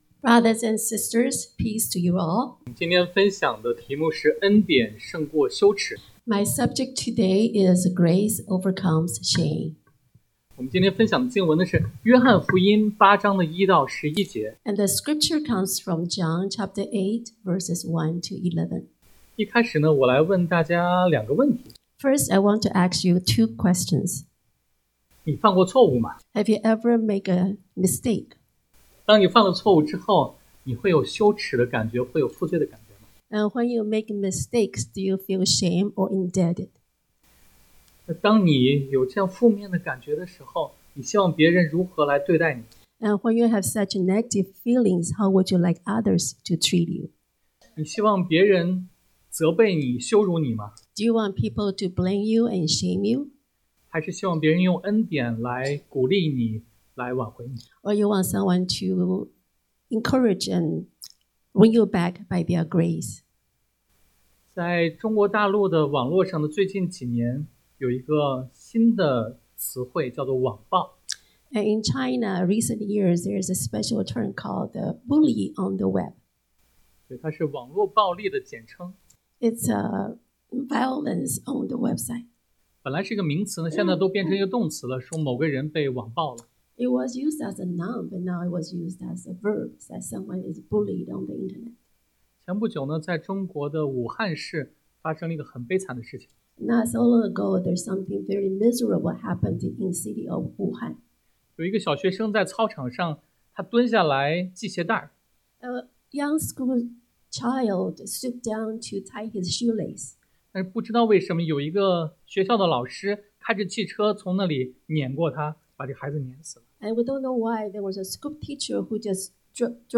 Passage: 约翰福音 John 8:1-11 Service Type: Sunday AM